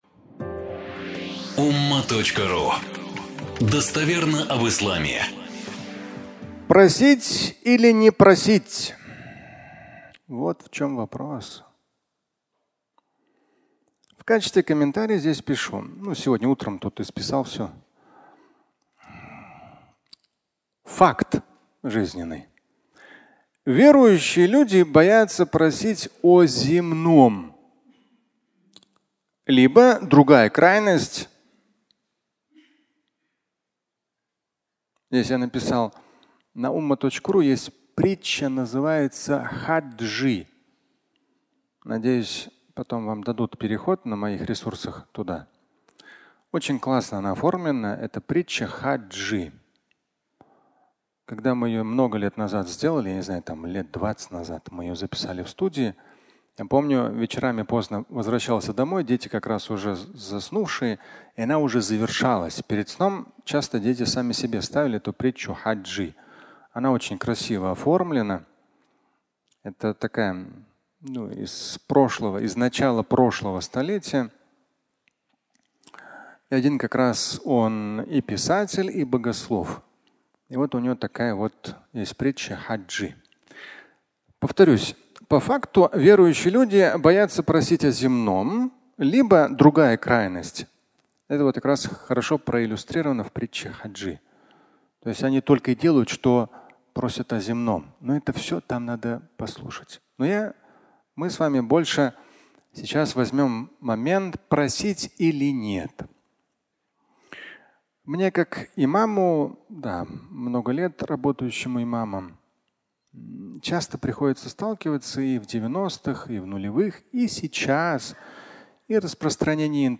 Просить или нет (аудиолекция)